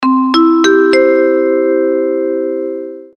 • Качество: 320, Stereo
без слов
звонкие
Звуки аэропорта